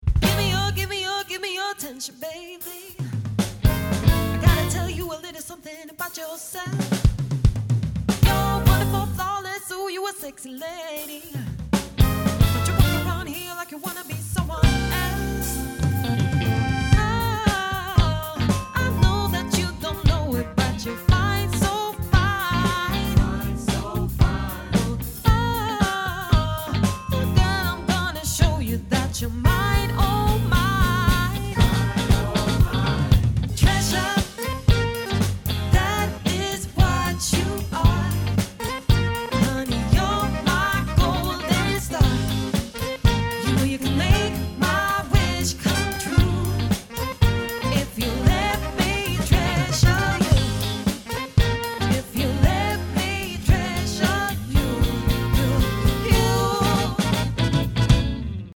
party/cover band
fem musiker